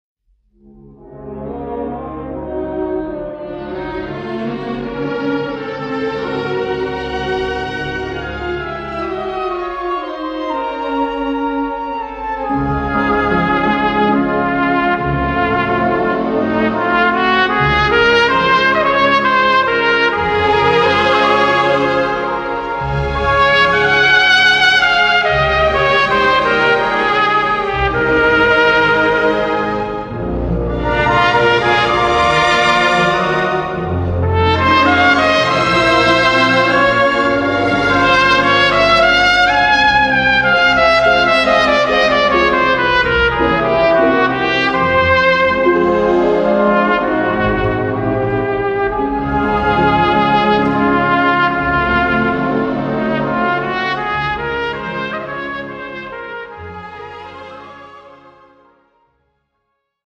Instrumentalnoten für Trompete